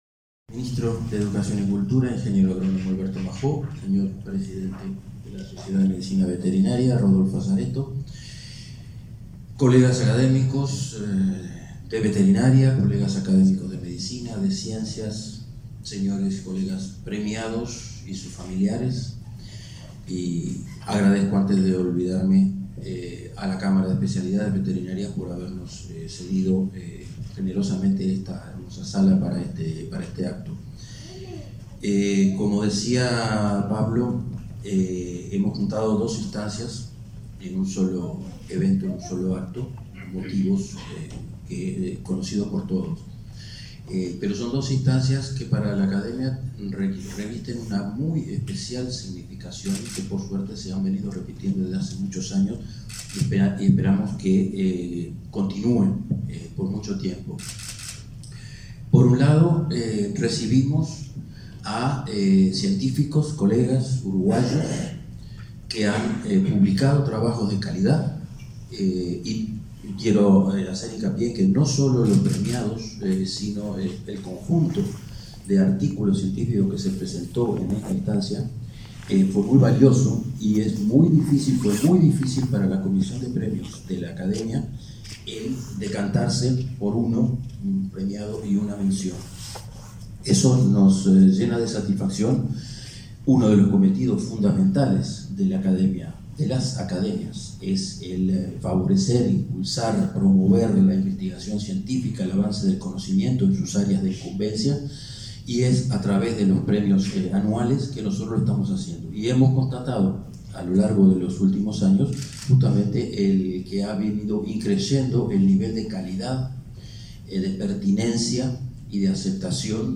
La Academia Nacional de Veterinaria (ANV) realizó el acto de nombramiento de nuevos académicos y de entrega de premios correspondientes a 2021, este 13 de junio.